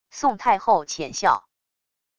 宋太后浅笑wav音频